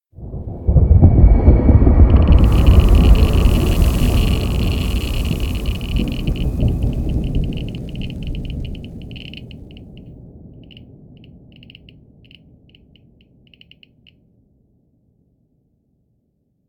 nuclear-explosion-aftershock-3.ogg